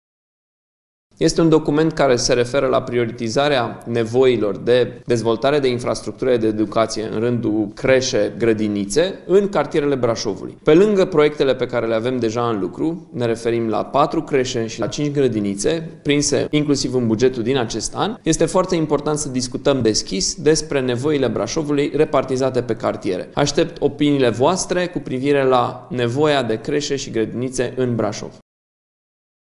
Primarul Allen Coliban: